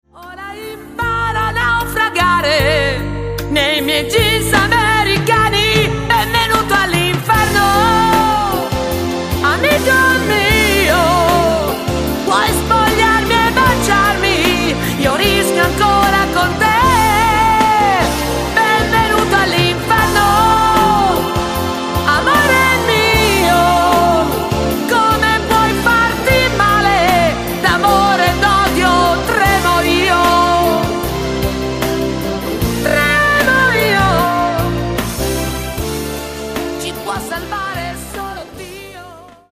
SLOW  (3.34)